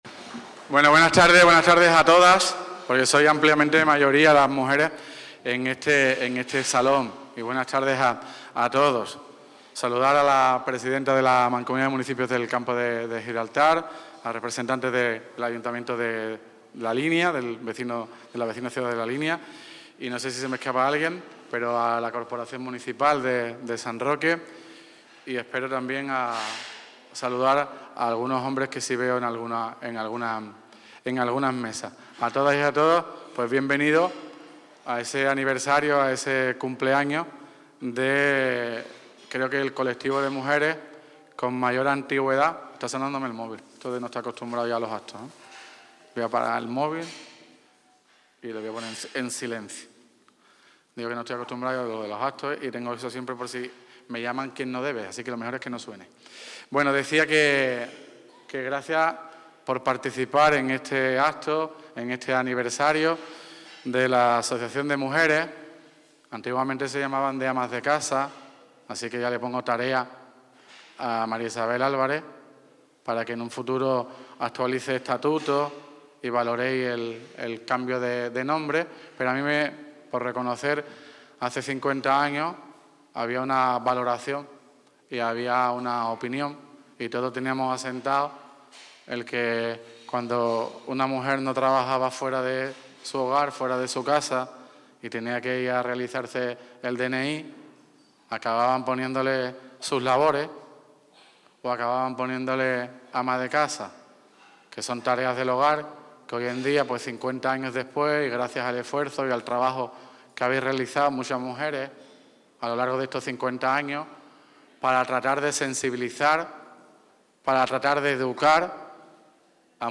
ANIVERSARIO CONSTANCIA (TOTAL ALCALDE) 5 MARZO 2026.mp3